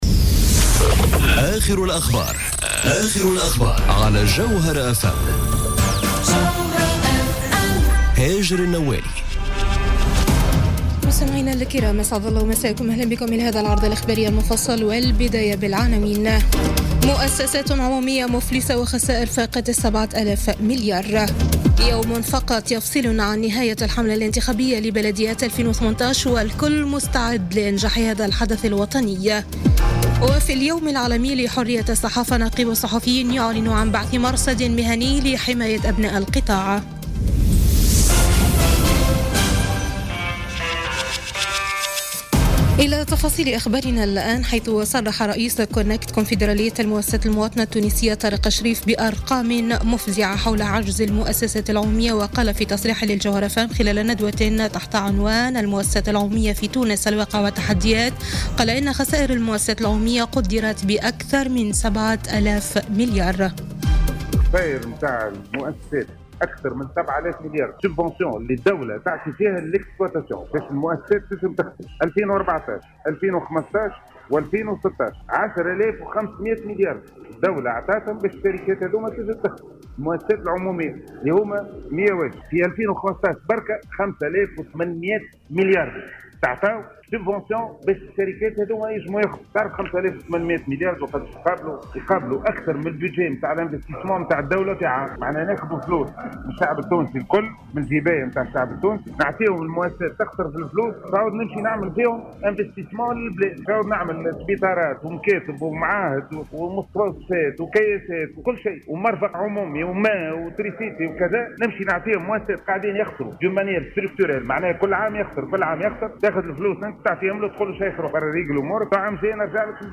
نشرة أخبار السابعة مساءً ليوم الخميس 3 ماي 2018